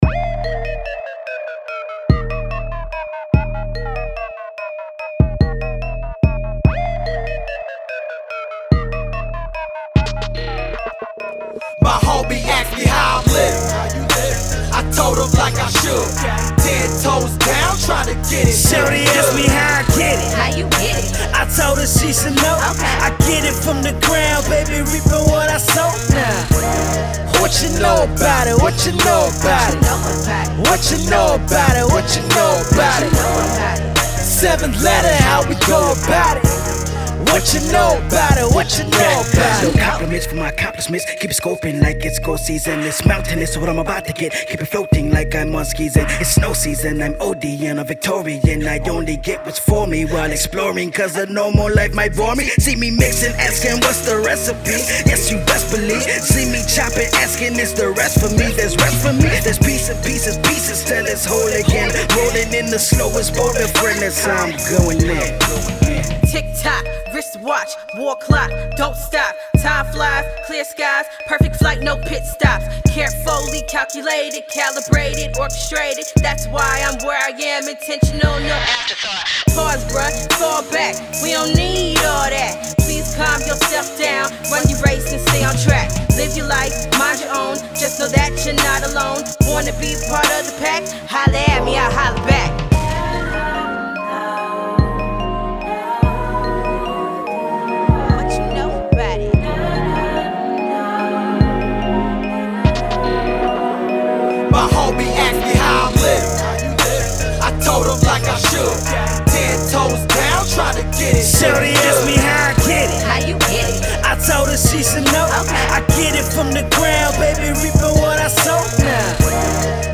Hip Hop band